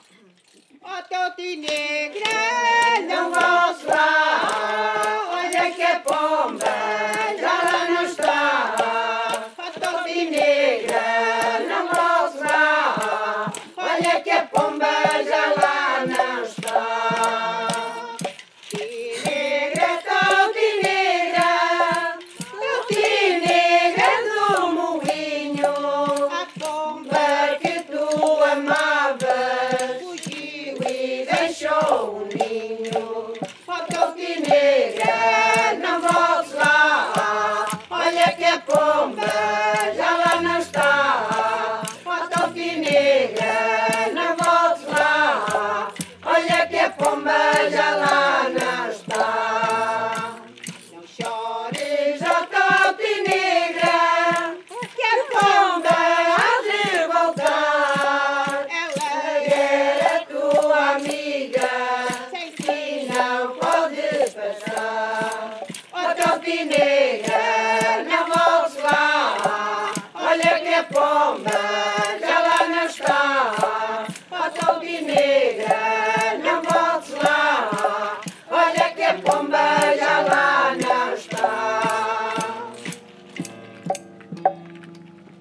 Grupo Etnográfico de Trajes e Cantares do Linho de Várzea de Calde
Toutinegra (Várzea de Calde, Viseu)
Tipo de Registo: Som